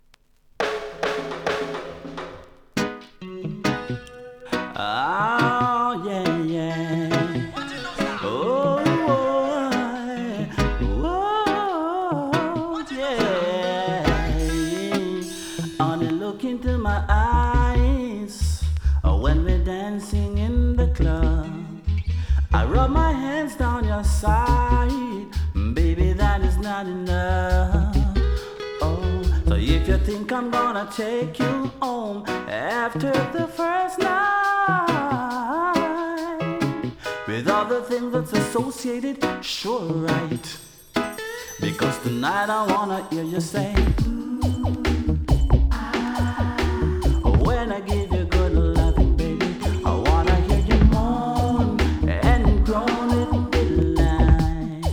REGGAE 90'S